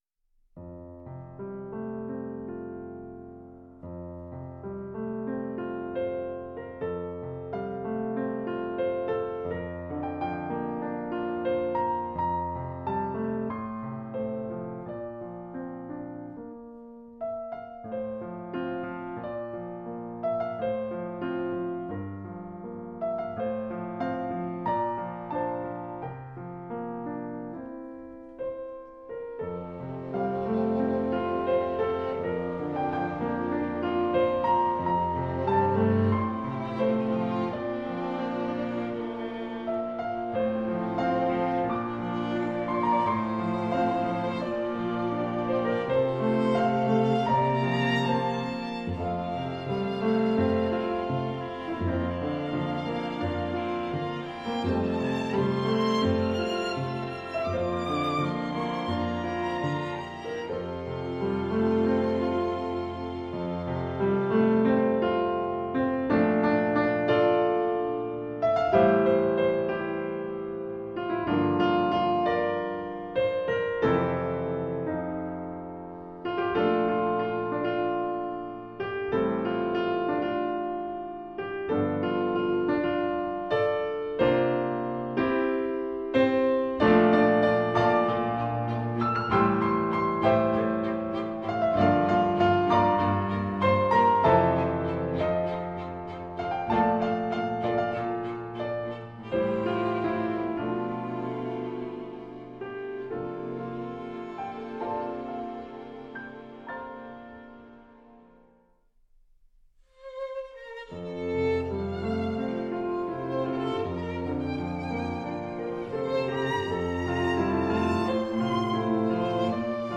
携世界名琴九尺斯坦威唯美呈现
记忆的深处，或许有丝弦碧绿的忧伤，还是可以采撷更多琴音的美好